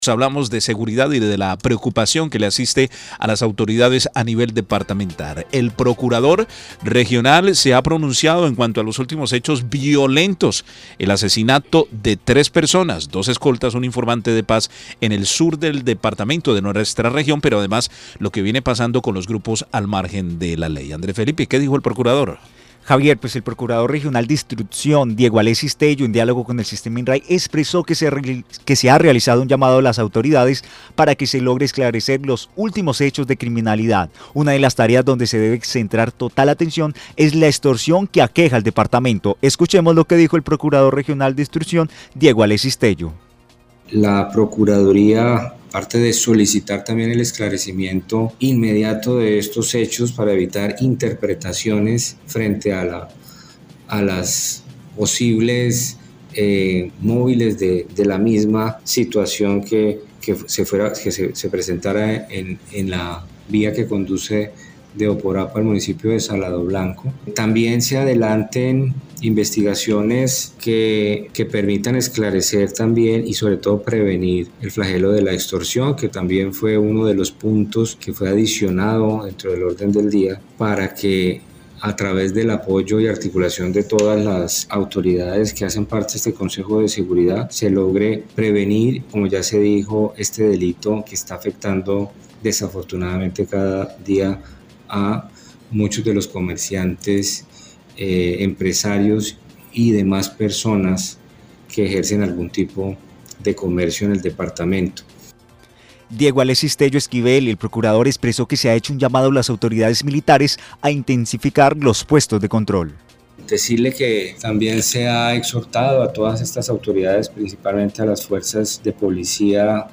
El procurador regional de instrucción Diego Alexis Tello en diálogo con el sistema INRAI expresó que se ha realizado un llamado a las autoridades para que se logre esclarecer los últimos hechos de criminalidad.